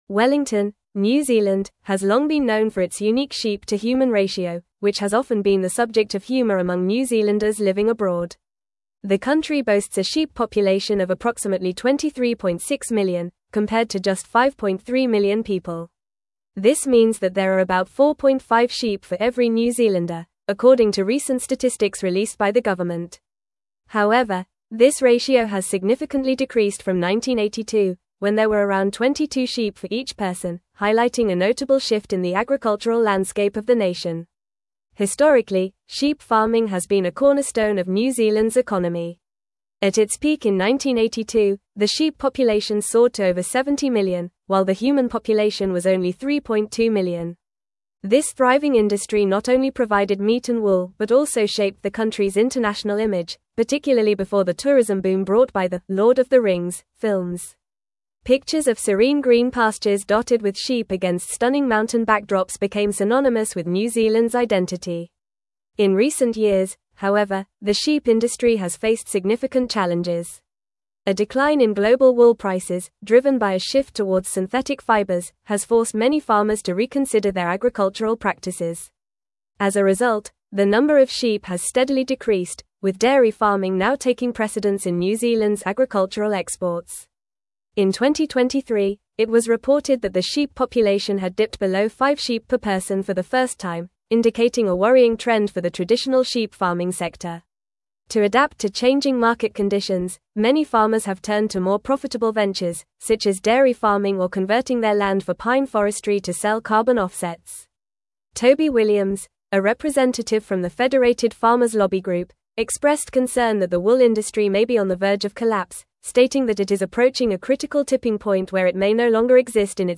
English-Newsroom-Advanced-FAST-Reading-Decline-of-New-Zealands-Sheep-Population-and-Industry.mp3